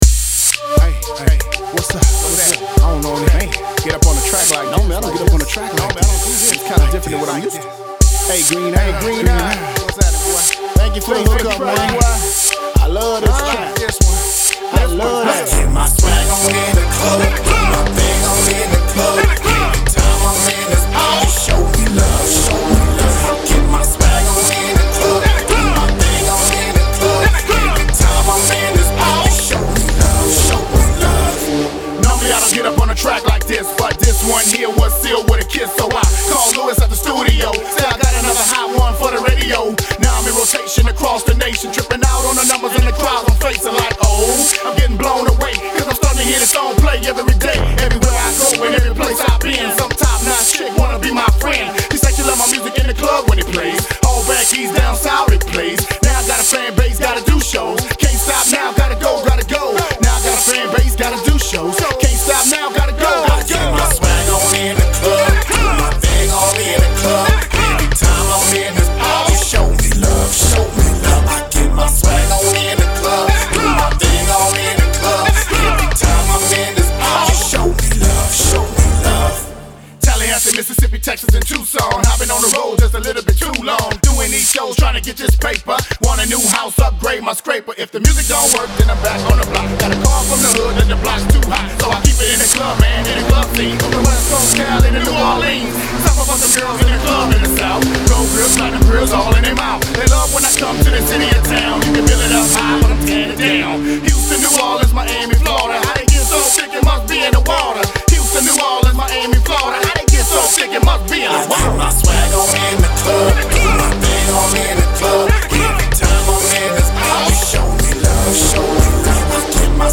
hip hop/ rap